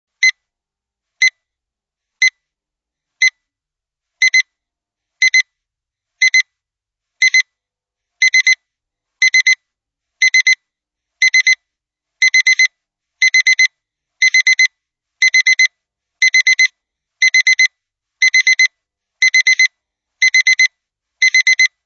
Extrait de la sonnerie:
Ascending-Beep-A.mp3